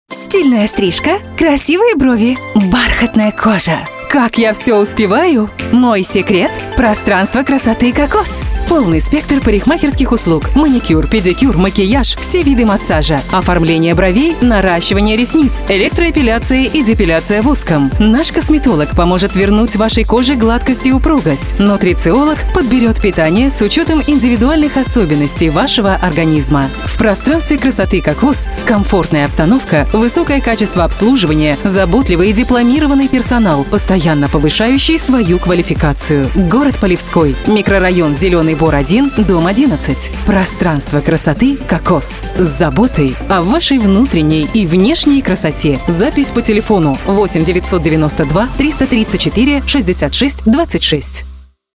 Жен, Рекламный ролик/Зрелый
Микрофон: RODE NT2-A, звуковая карта: Focusrite Scarlett 2Pre USB, профессиональная студия.